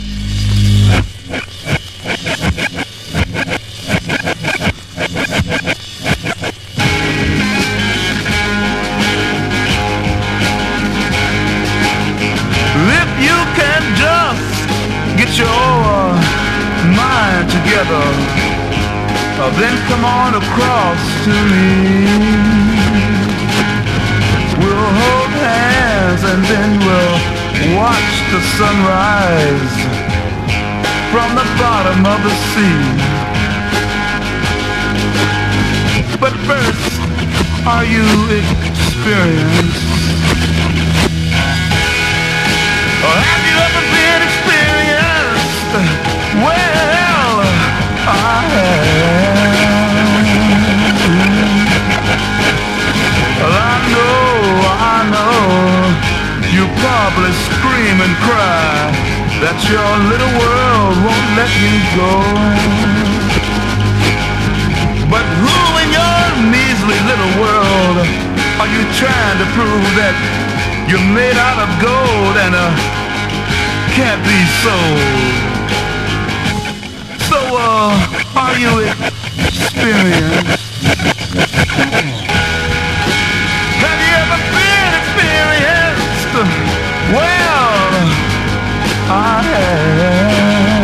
ROCK / SOFTROCK. / PSYCHEDELIC
清らかなフォーキィ・ソフトロック/サイケデリック・ハーモニー・ポップの名曲ズラリ！
たおやかなメロが爽快な男女混声ハーモニーで紡がれる
眩しく鮮やかなコーラス・ワークで紡がれる